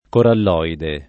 coralloide [ korall 0 ide ] agg.